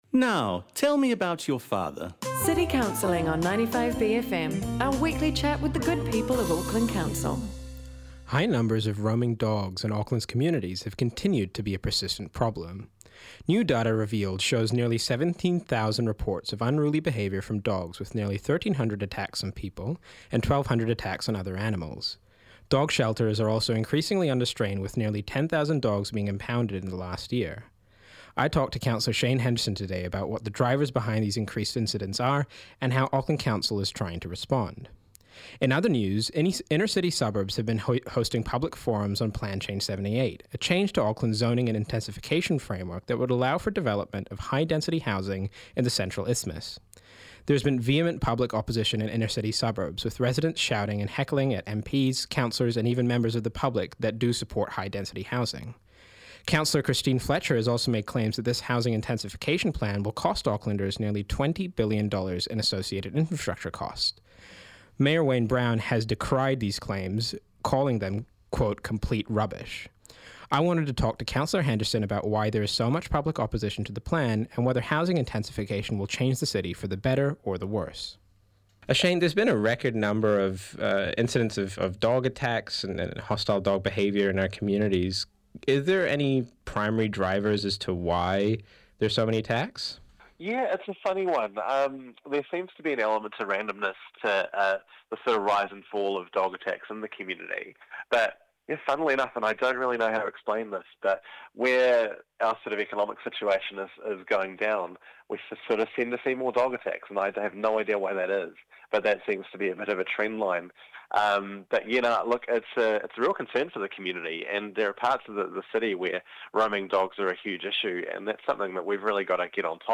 I talked to Councilor Shane Henderson today about what the drivers behind these increased incidents and how Auckland Council is trying to respond.